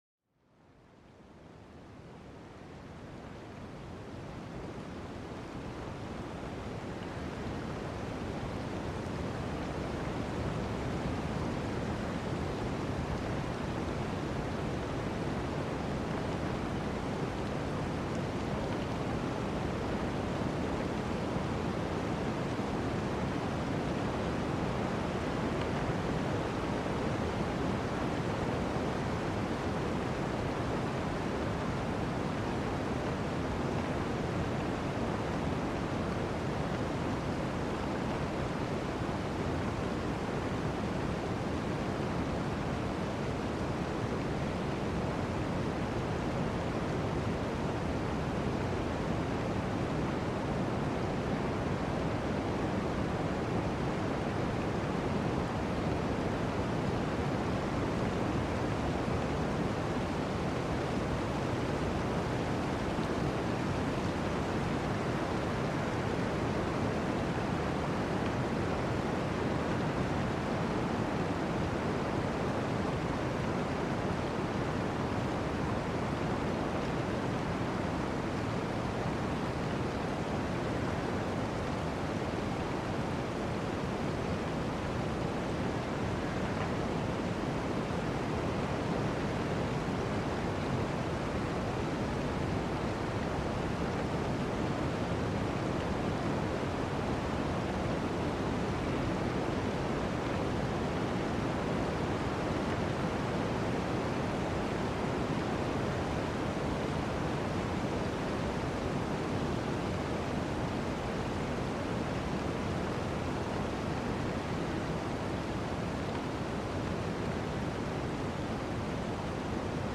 Sumérgete en el corazón de un bosque donde el viento susurra entre los árboles, una sinfonía natural relajante. Déjate llevar por el murmullo de las hojas, una dulce melodía que apacigua el alma.